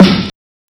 SNARE PUNCH.wav